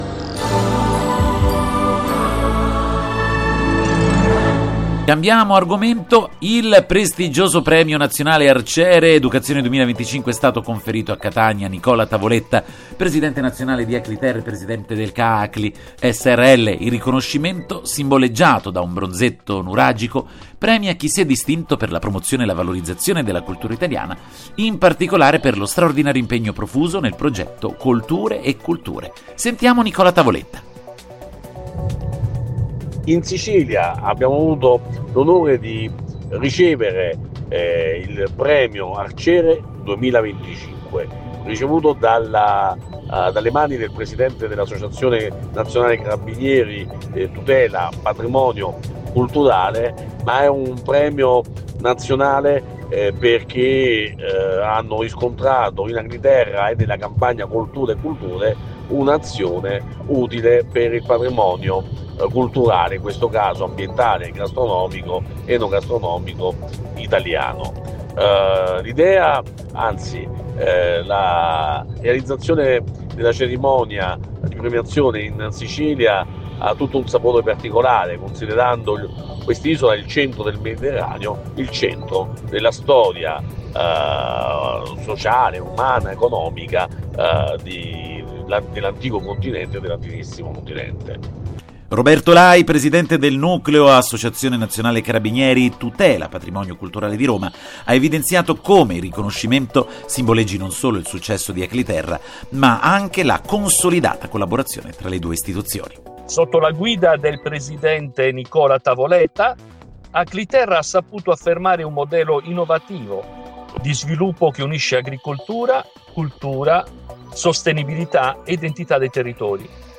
(dal gr nazionale)